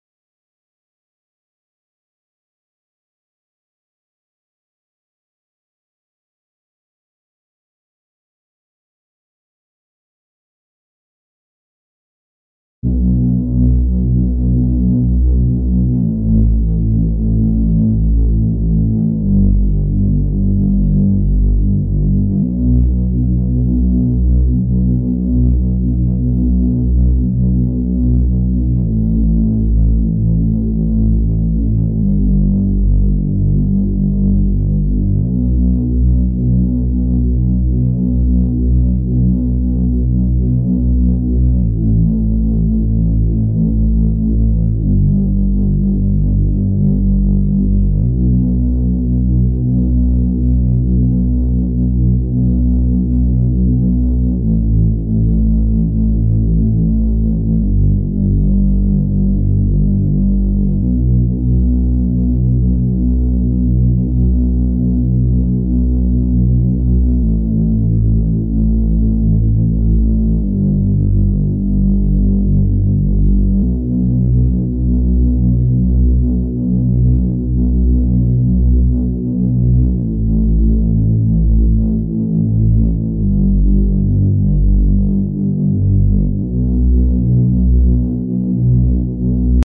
🔹 50 Custom Serum Presets crafted for wave pop, ambient electronica, and deep emotional beats.
These presets feel like water: fluid, lush, and endlessly immersive.
Smooth, Wide Basses – Warm subs and silky mid-lows that glue your mix